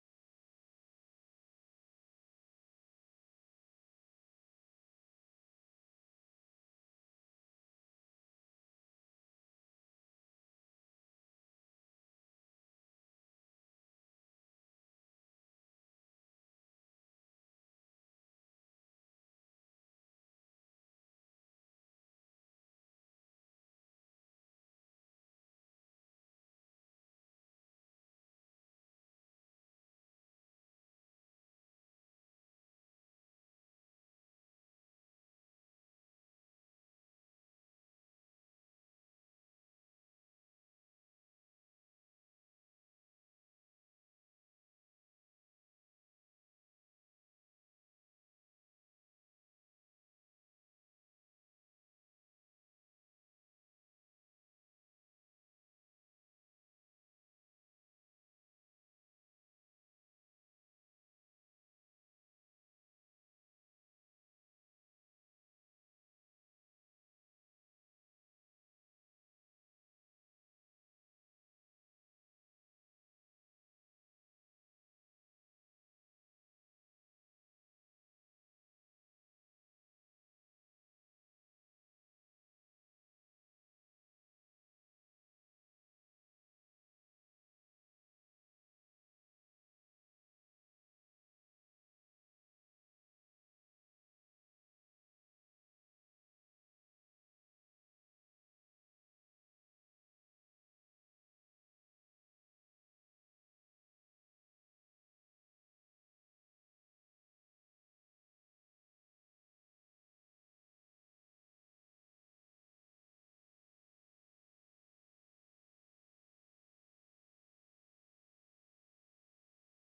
Worship and Praise from FWC August 18 2024